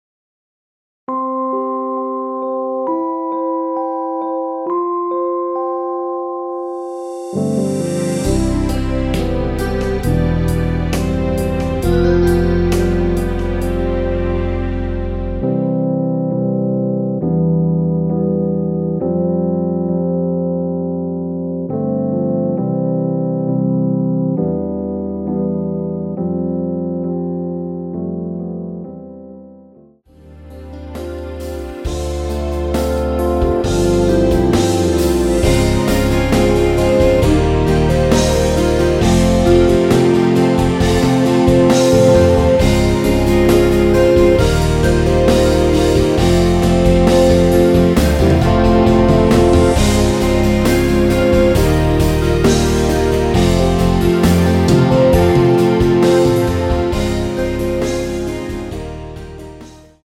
원키에서(-2)내린 MR입니다.
◈ 곡명 옆 (-1)은 반음 내림, (+1)은 반음 올림 입니다.
앞부분30초, 뒷부분30초씩 편집해서 올려 드리고 있습니다.
중간에 음이 끈어지고 다시 나오는 이유는